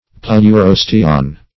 Search Result for " pleurosteon" : The Collaborative International Dictionary of English v.0.48: Pleurosteon \Pleu*ros"te*on\, n.; pl.
pleurosteon.mp3